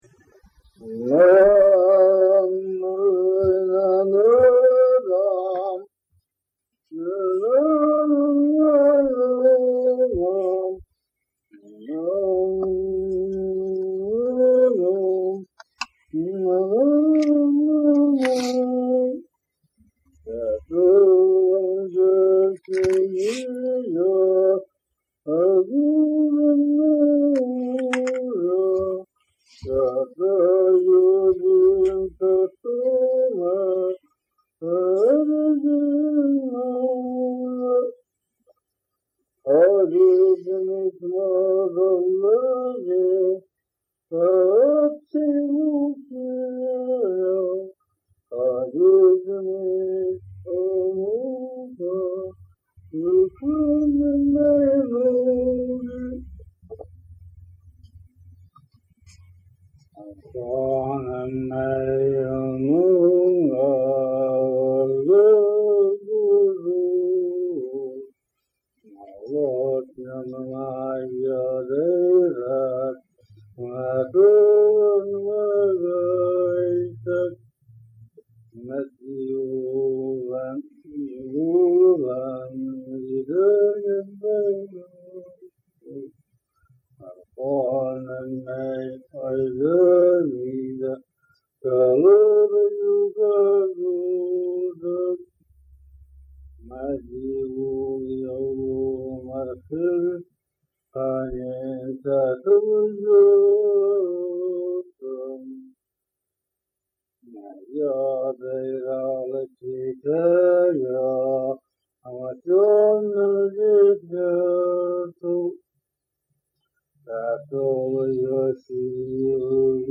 Online Kolyma Yukaghir Documentation